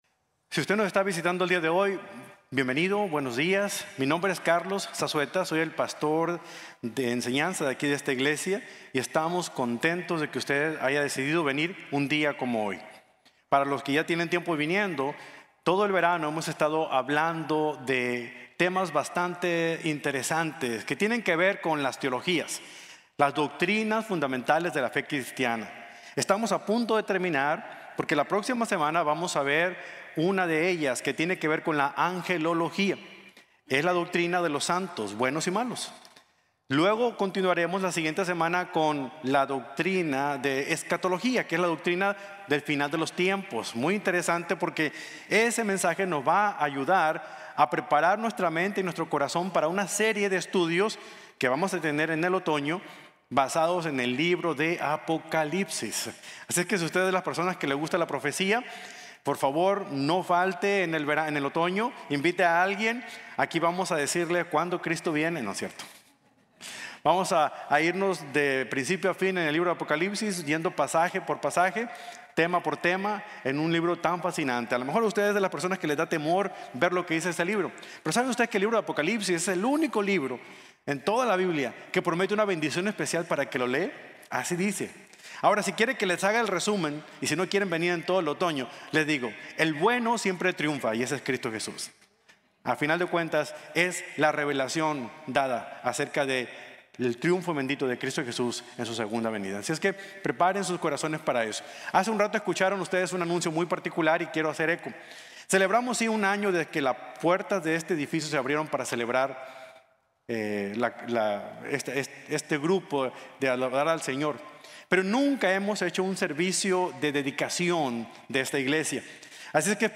la Doctrina de la Salvación | Sermon | Grace Bible Church